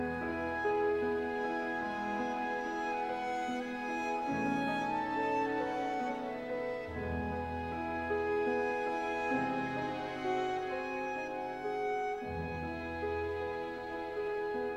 Mi Majeur. 4/4.